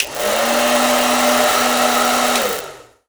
Blow Dryer 05
Blow Dryer 05.wav